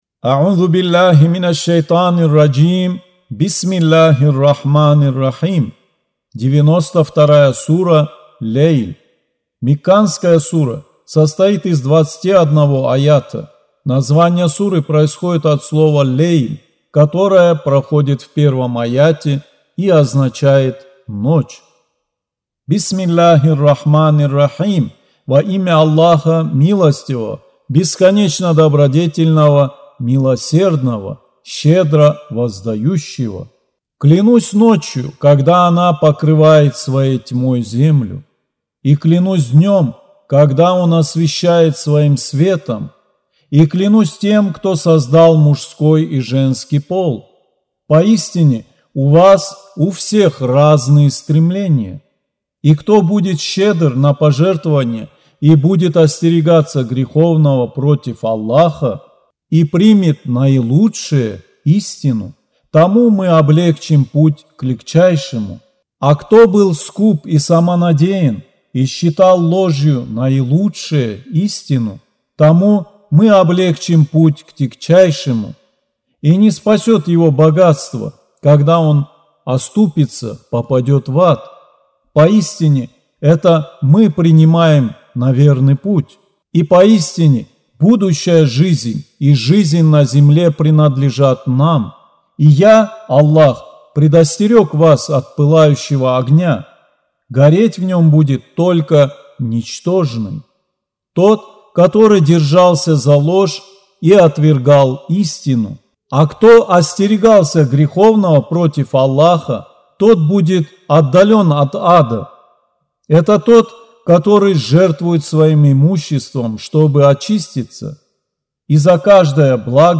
Аудио Коран 92.